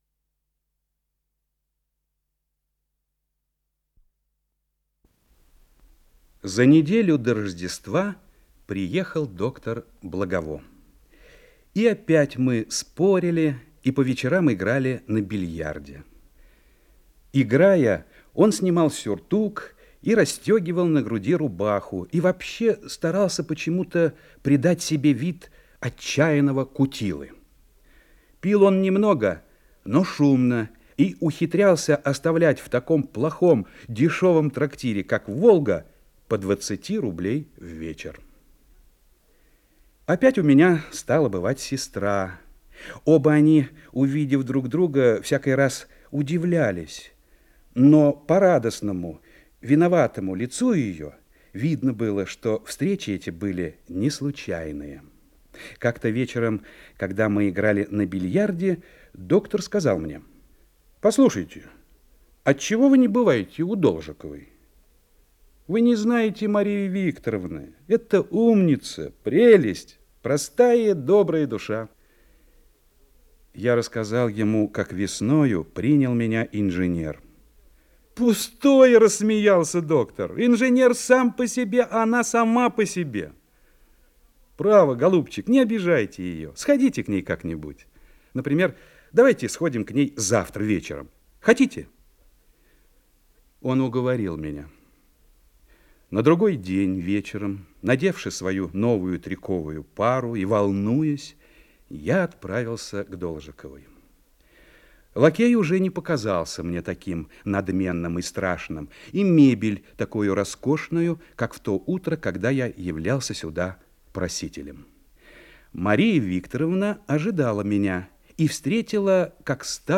Исполнитель: Вячеслав Тихонов - чтение
Рассказ